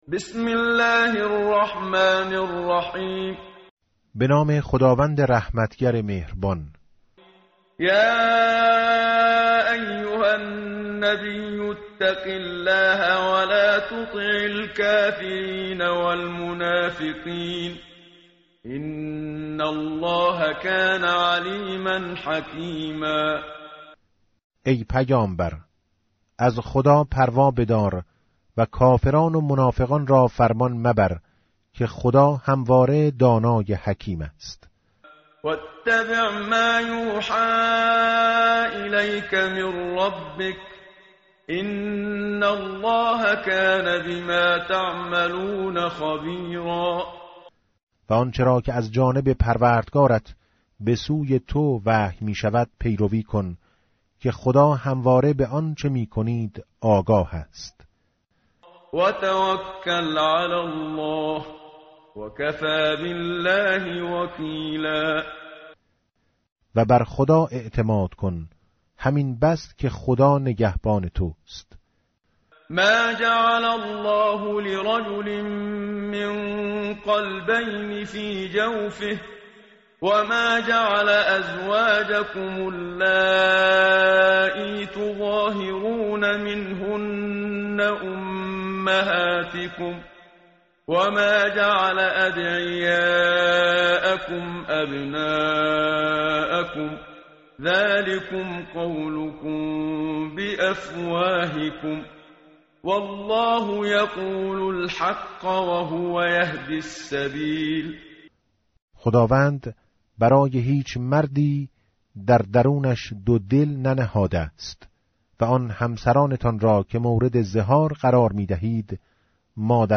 tartil_menshavi va tarjome_Page_418.mp3